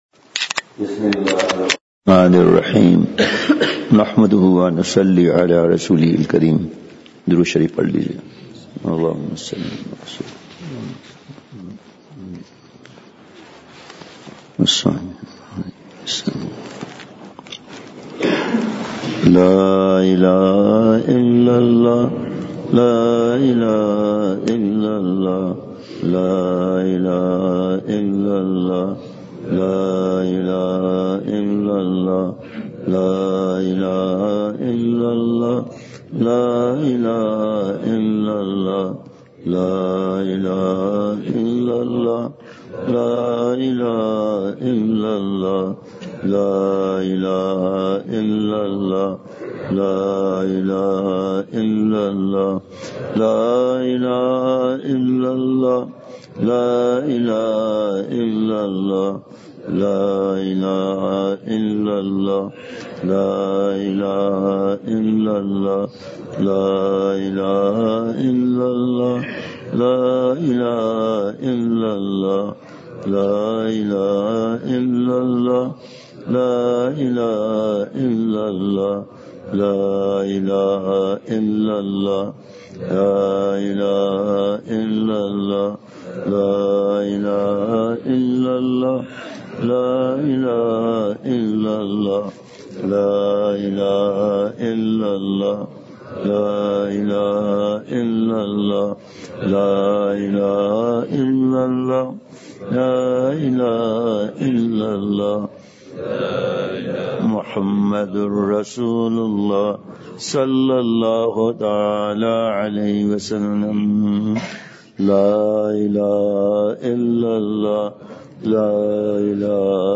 مقام۔ جامعہ تعلیم القرآن تختہ بند سوات عنوان:نظر کی حفاظت کے عظیم الشان مضمون کی اہمیت/امارد سے احتیاط کے مضمون کی اہمیت
مدرسہ سے متصل مسجد میں بیان